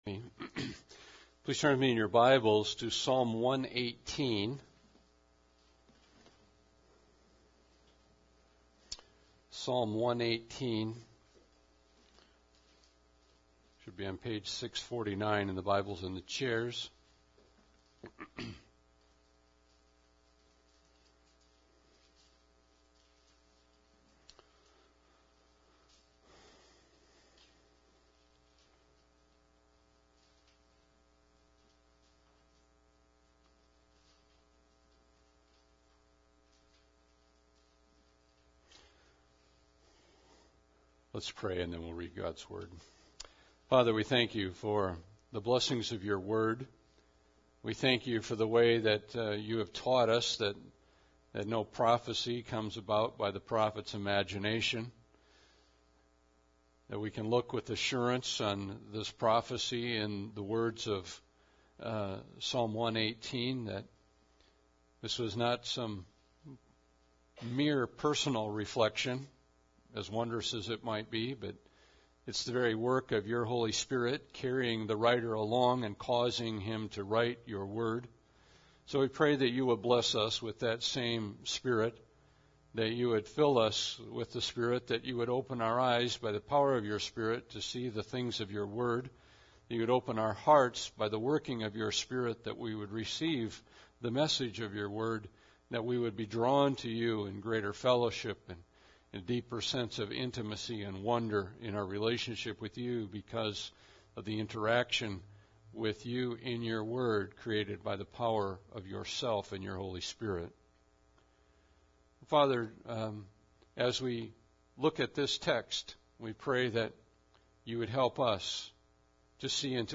Passage: Psalms 118 Service Type: Sunday Service